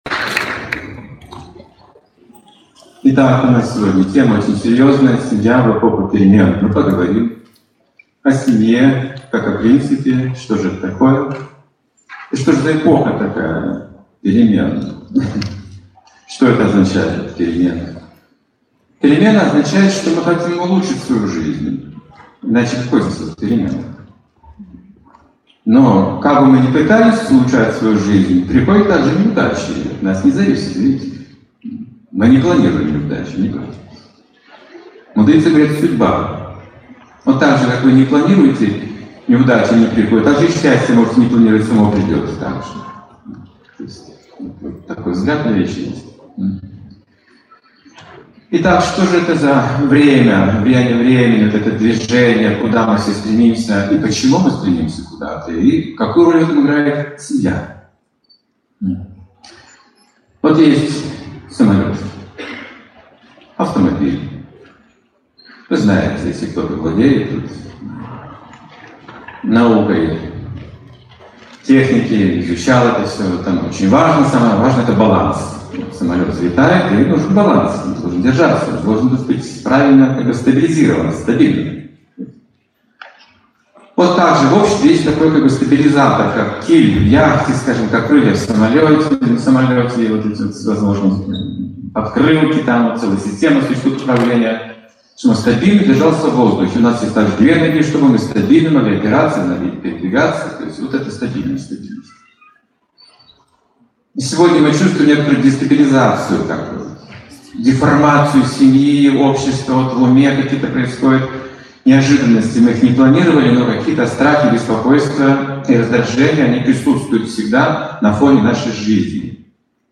Темы, затронутые в лекции: Перемены и неудачи Эгоизм в отношениях Институт брака Память сердца Как мужчине сохранить энергию Внутренний мир мужчины и женщины Любовь в сердце Отрегулировать жизнь Полюбить жизнь Разные типы веры Цитаты: Счастье имеет возрастающую природу.